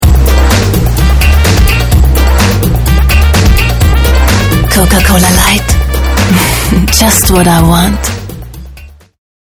deutsche Sprecherin.
Sprechprobe: Sonstiges (Muttersprache):
german female voice over artist, dubbing